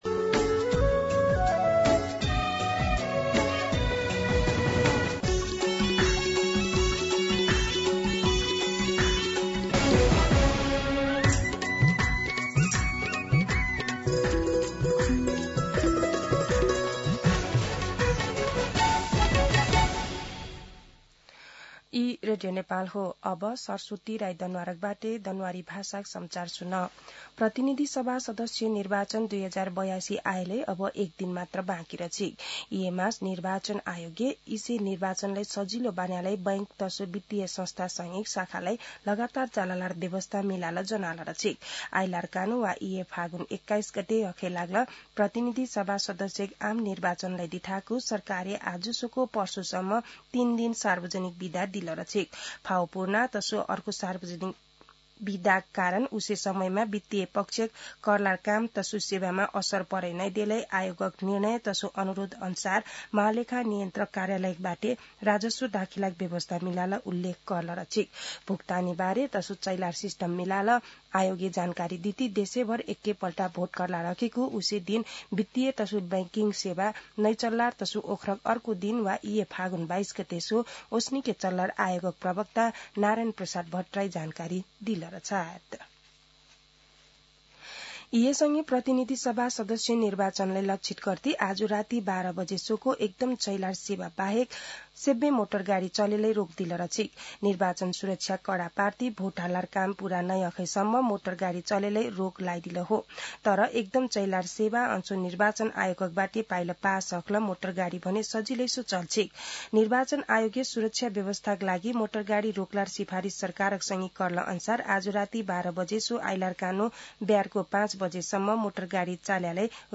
दनुवार भाषामा समाचार : २० फागुन , २०८२
danuwar-news.mp3